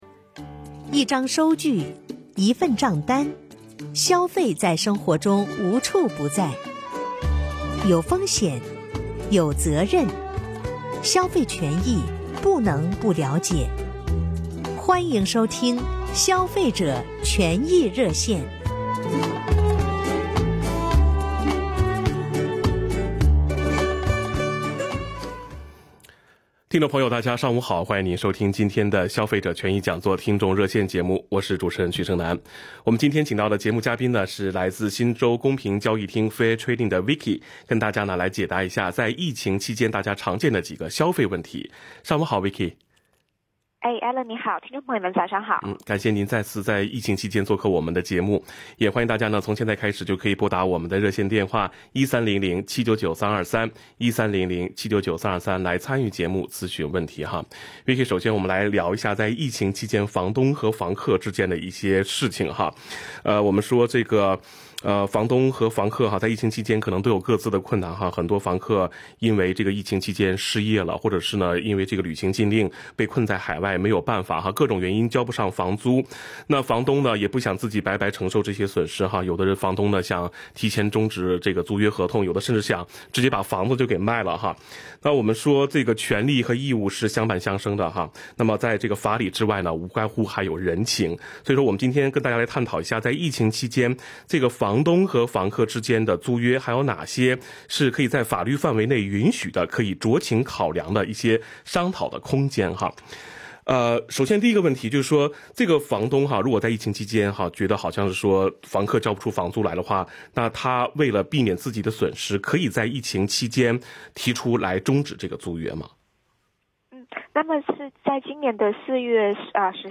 本期《消费者权益讲座》听众热线
consumers_talkback_may_11.mp3